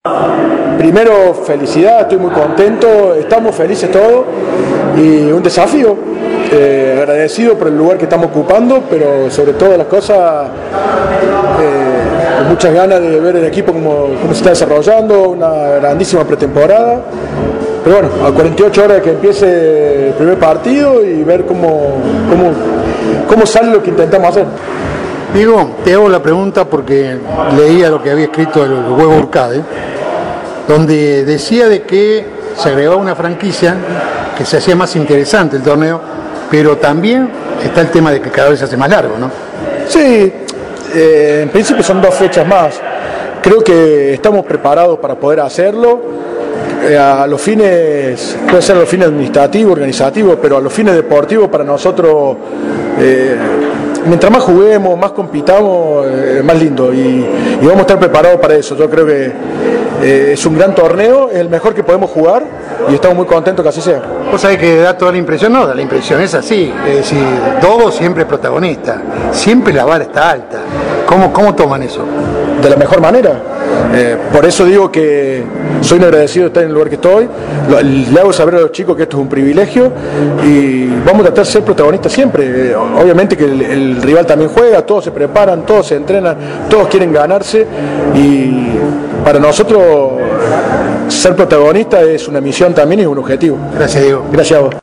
En la presentación de Los Dogos en el estadio Mario Kempes, antes del debut con Cobras de Brasil